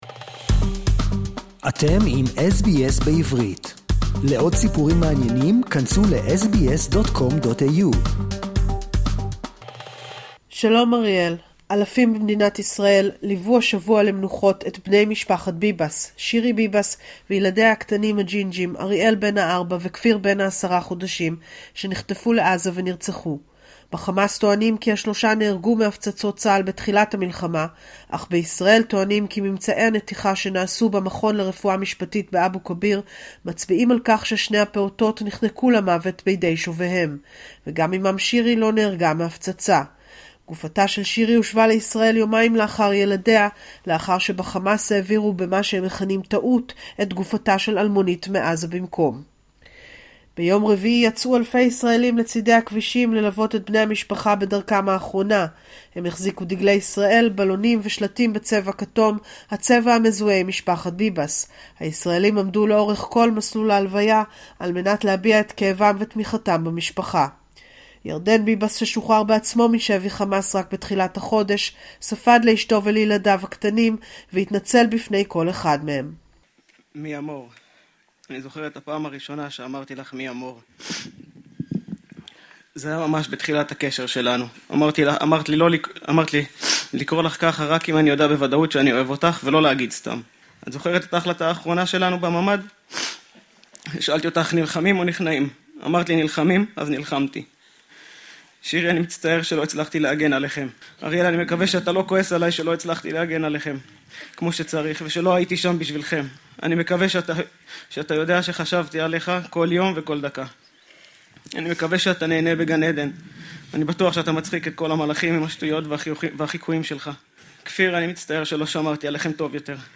Latest News Update from Israel